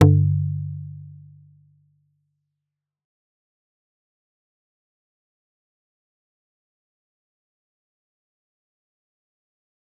G_Kalimba-G2-f.wav